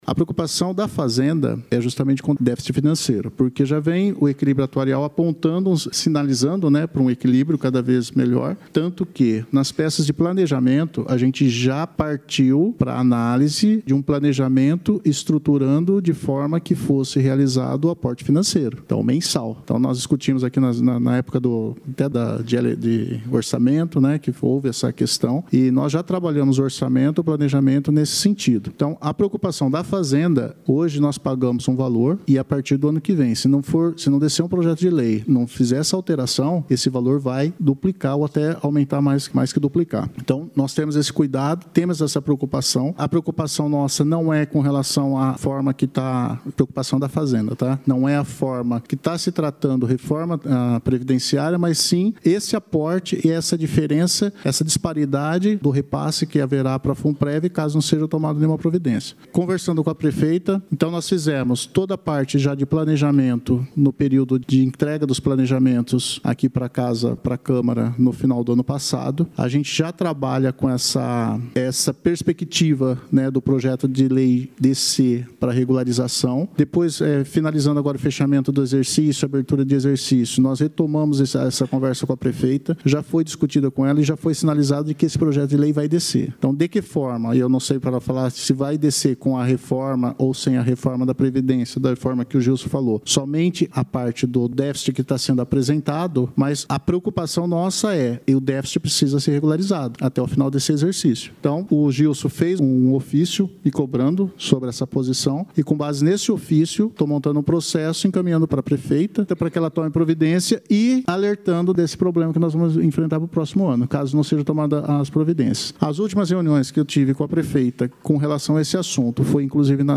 Prefeita Suéllen Rosim enviará à Câmara, projeto de lei da reforma previdenciária. A informação foi confirmada pelo secretário da fazenda, Everson Demarchi, durante audiência pública realizada na tarde desta terça-feira, na Câmara Municipal.